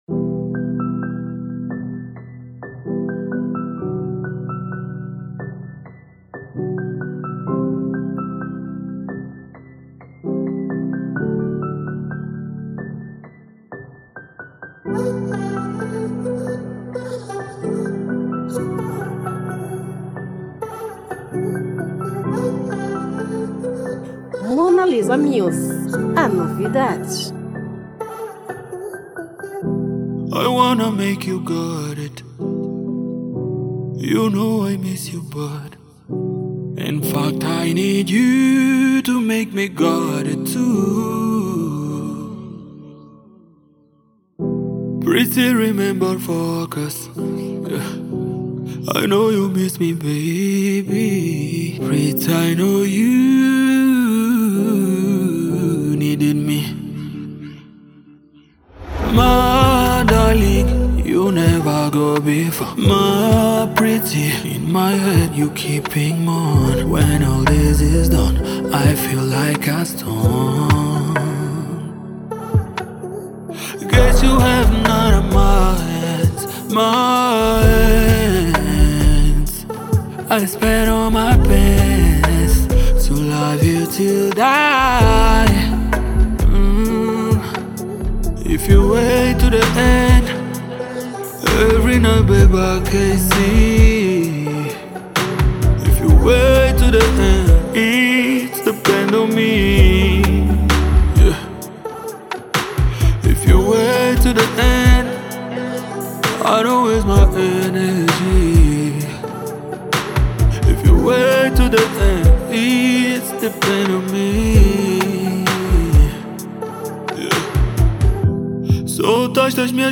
Gênero : Zouk